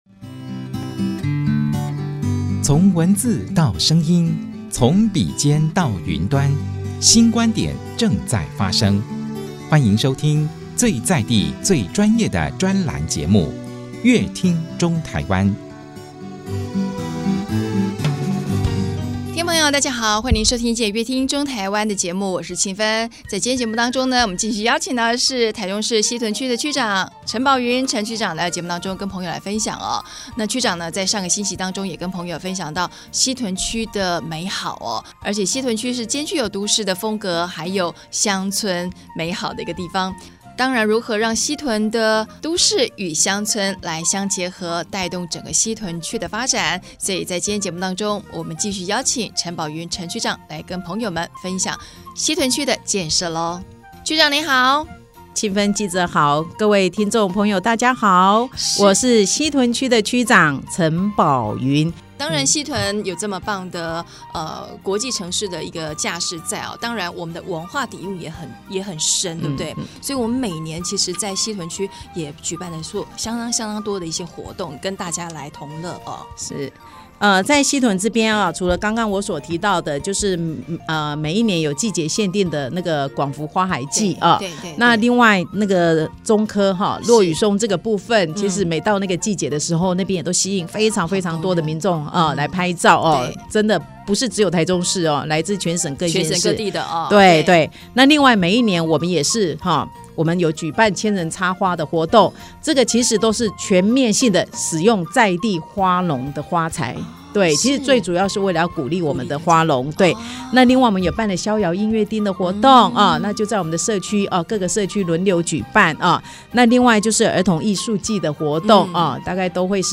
本集來賓：台中市西屯區陳寶雲區長 本集主題：「西屯區的美好」 本集內容： 一頭短髮，永遠笑臉迎人，她是被西屯區